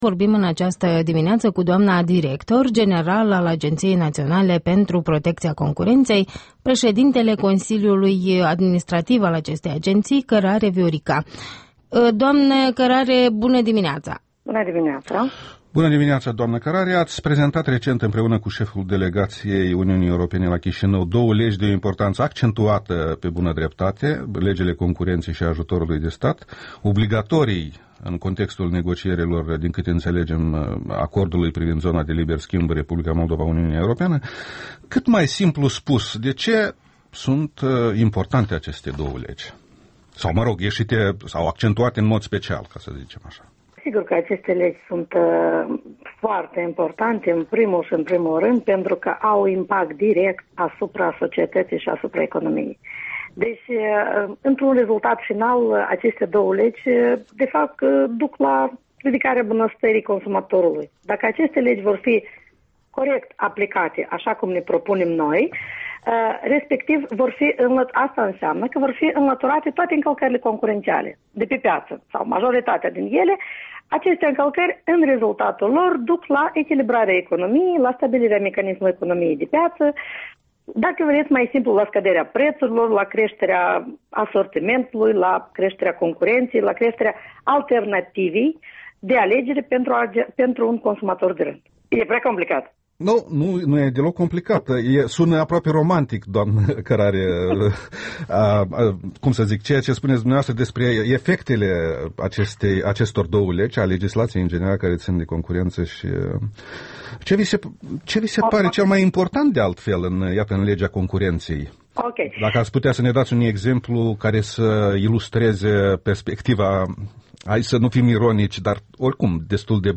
Interviul dimineții la EL: cu Viorica Cărare despre o legislație nouă referitoare la concurență